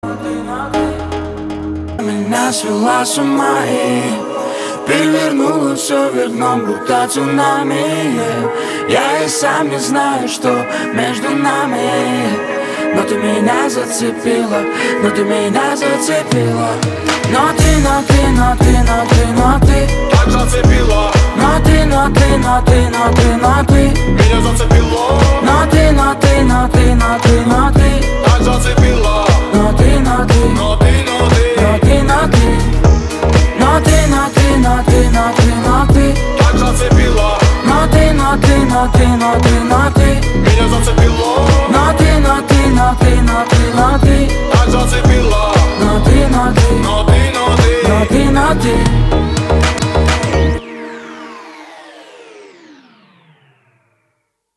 • Качество: 320, Stereo
лирика
русский рэп
чувственные